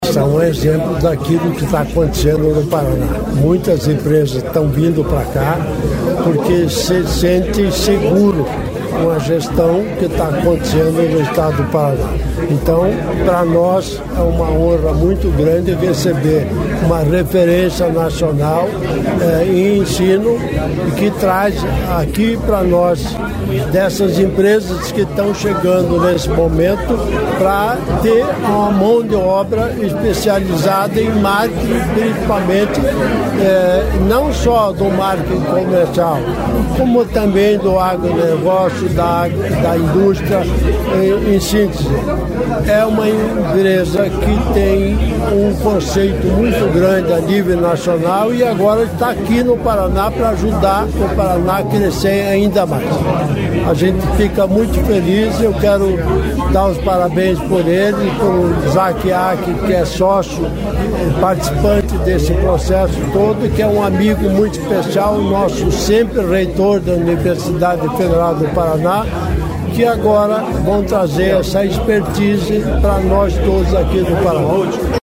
Sonora do governador em exercício Darci Piana sobre a expansão da atuação da ESPM para o Paraná